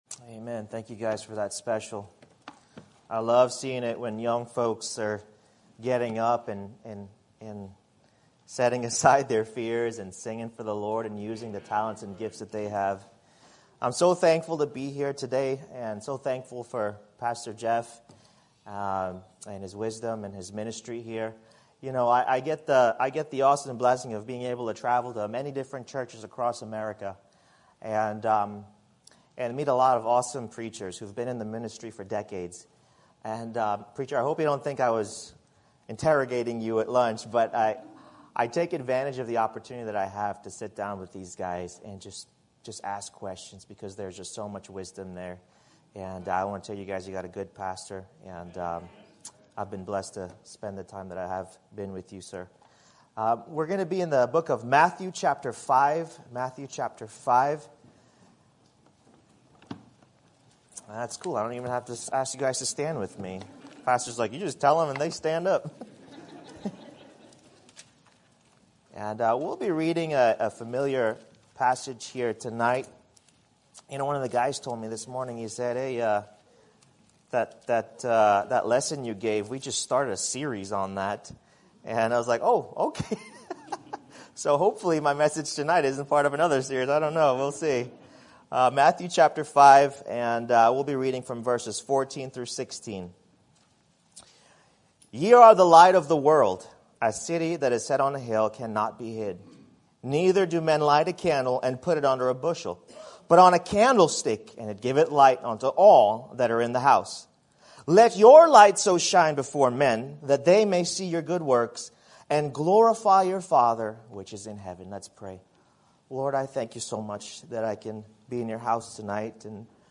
Sermon Topic: General Sermon Type: Service Sermon Audio: Sermon download: Download (10.84 MB) Sermon Tags: Matthew Missions Shine Light